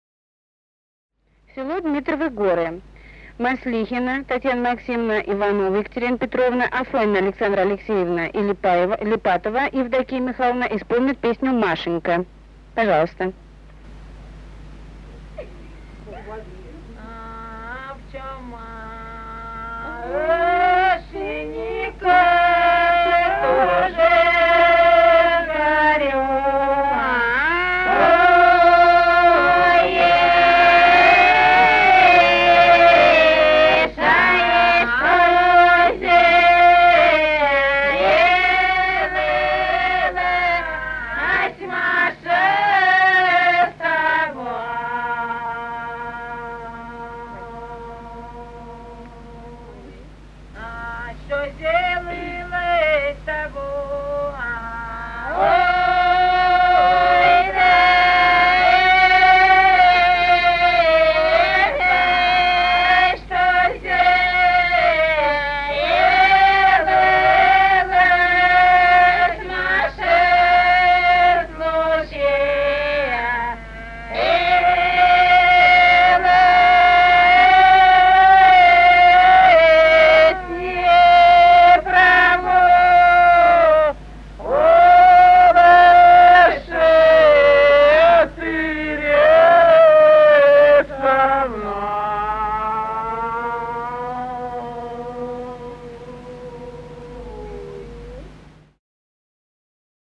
Русские народные песни Владимирской области 4а. Об чём, Машенька, тужишь-горюешь (лирическая) с. Дмитриевы Горы Ляховского (с 1963 Меленковского) района Владимирской области.